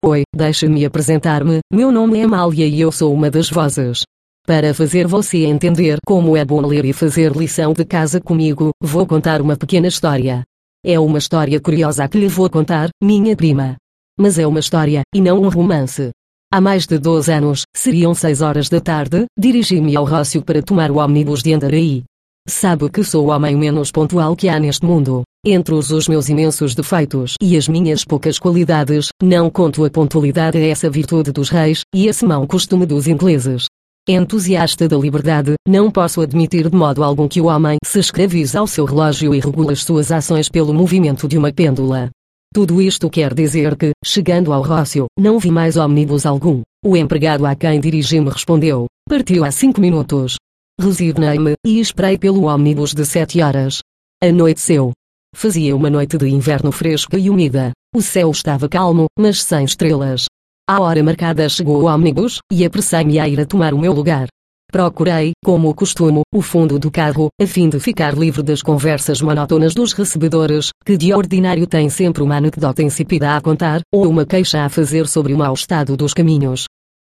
Per questo motivo, la voce risulta estremamente gradevole, chiara e fluente e priva del timbro metallico tipico delle voci artificiali.
Voce portoghese Amalia
Amalia-veloce.mp3